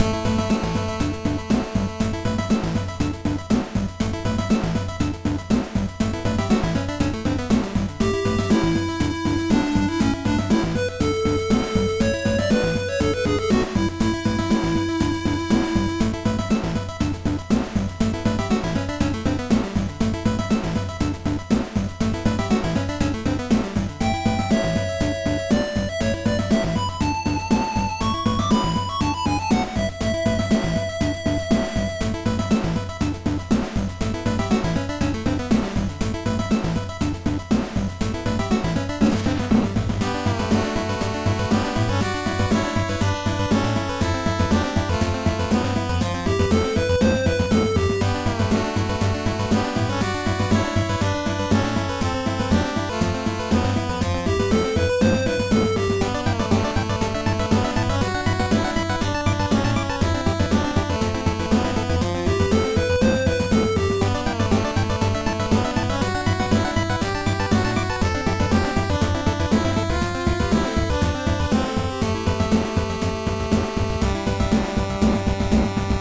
An 8-bit adventure theme that I think would fit for a variety of scenarios.
Made with Famitracker.